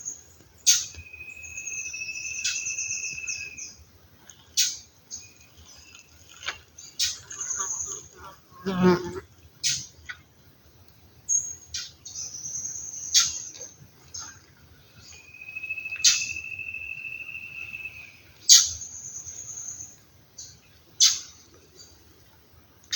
Rufous Gnateater (Conopophaga lineata)
Country: Brazil
Detailed location: Parque estadual do turvo
Condition: Wild
Certainty: Observed, Recorded vocal